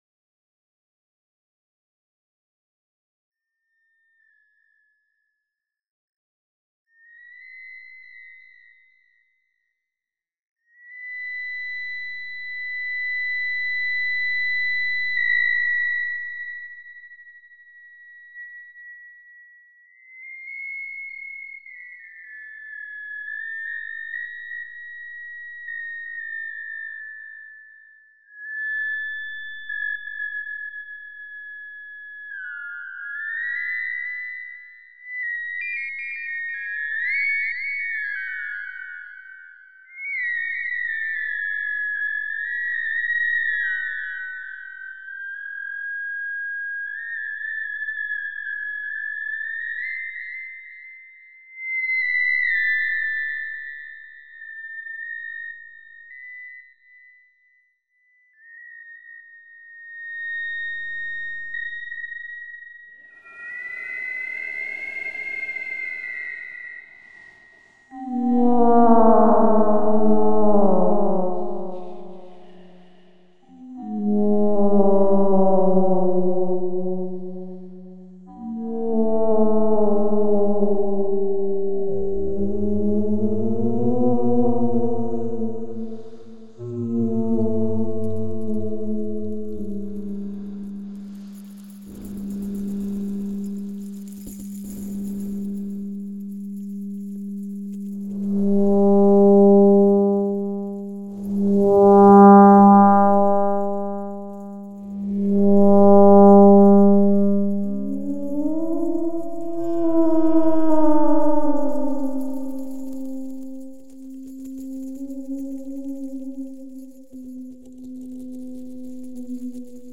audioMay 20 2003 Brooklyn College Center for Computer Music